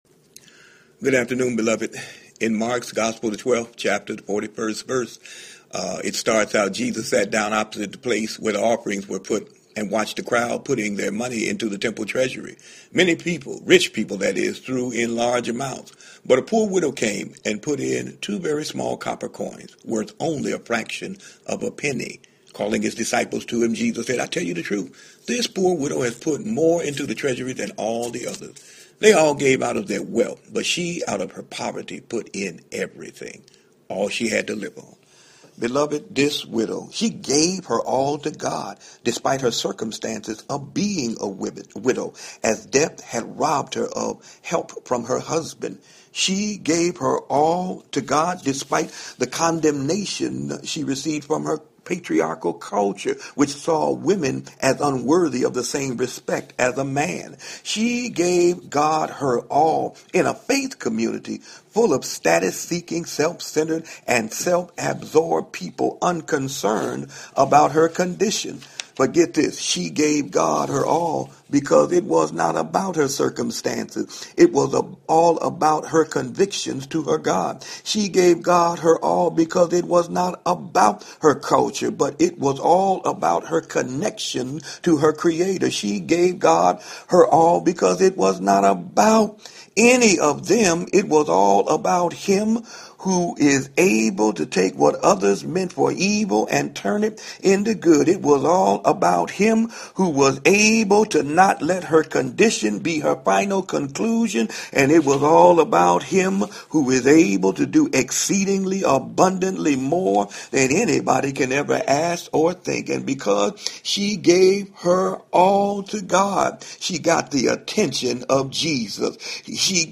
Hump Day Homily